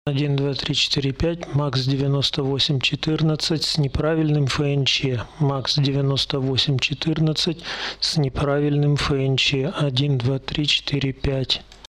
Усиление стоит 40дБ.
Выкладываю файл с "неправильным ФНЧ".